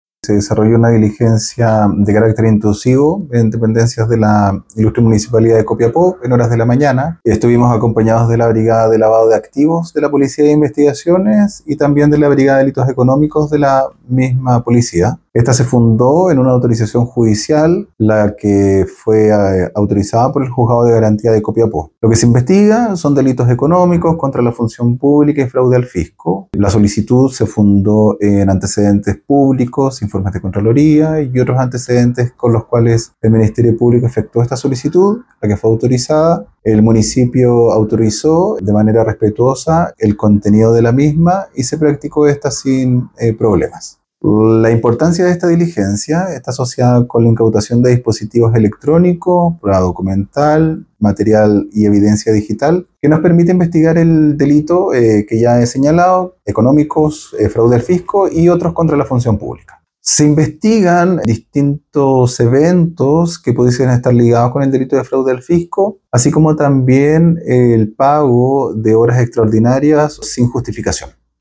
FISCAL-LUIS-MIRANDA.mp3